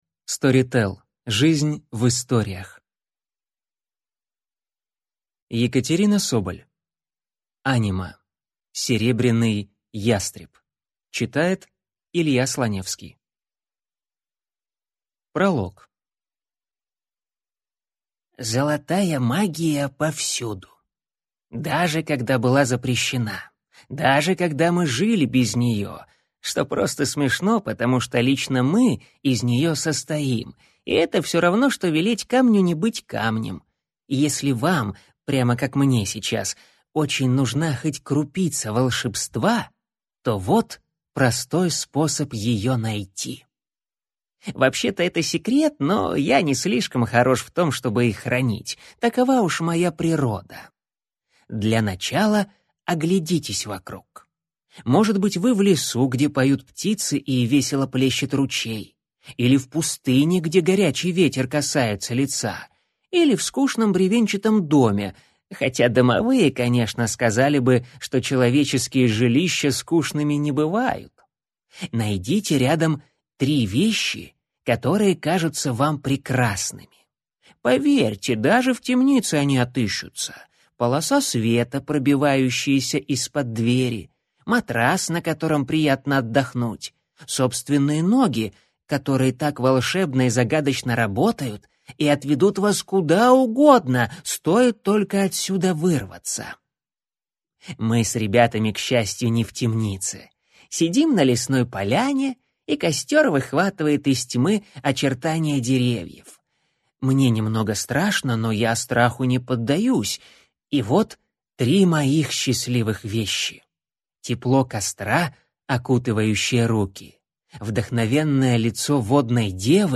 Аудиокнига Серебряный ястреб | Библиотека аудиокниг